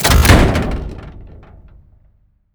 reload1.wav